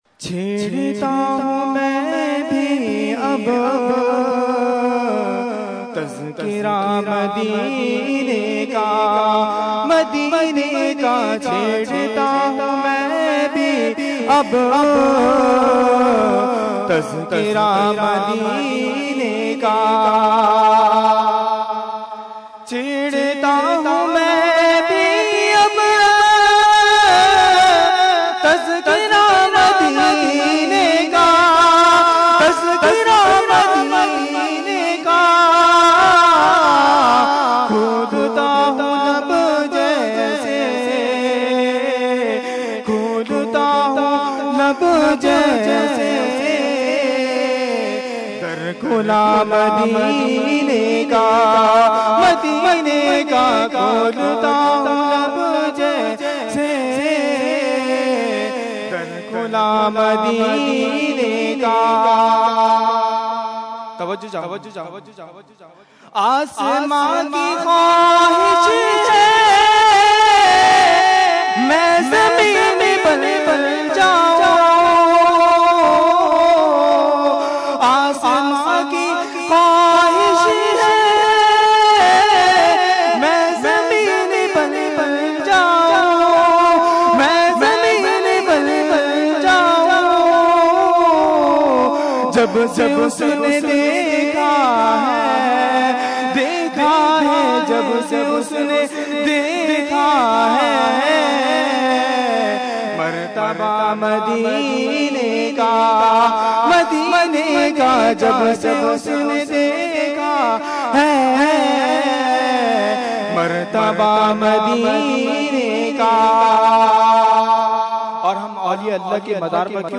Category : Naat | Language : UrduEvent : Urs Ashraful Mashaikh 2014